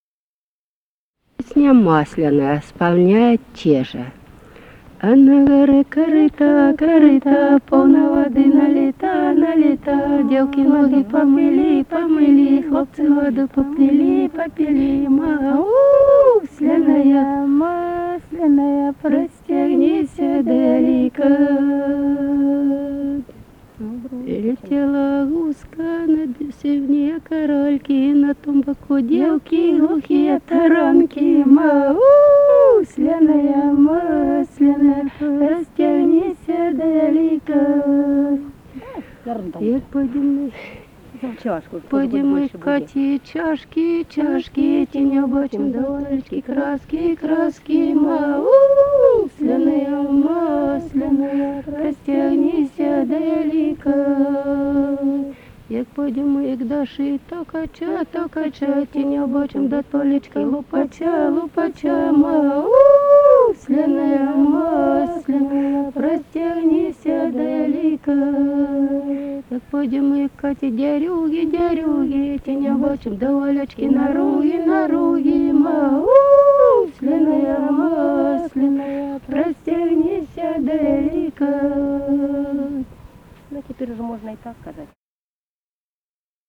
Музыкальный фольклор Климовского района 050. «А на горэ корыто» (масленая).
Записали участники экспедиции